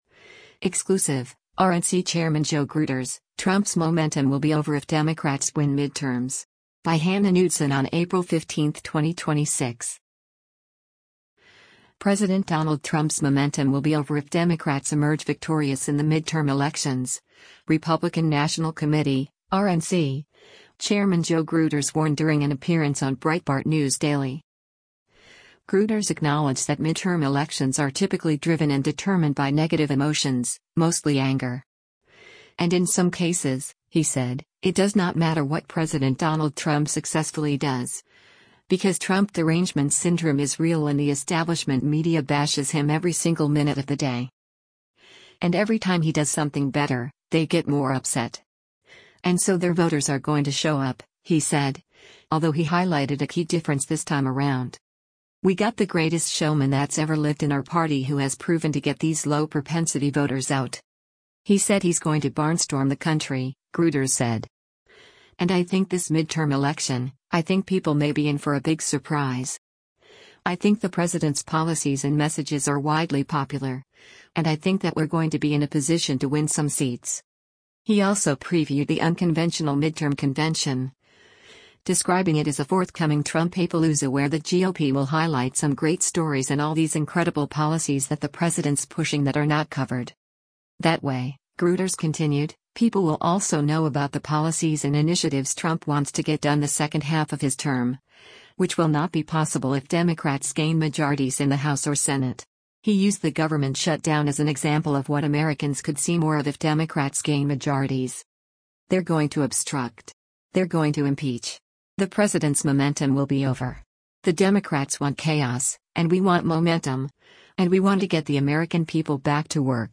President Donald Trump’s momentum “will be over” if Democrats emerge victorious in the midterm elections, Republican National Committee (RNC) Chairman Joe Gruters warned during an appearance on Breitbart News Daily.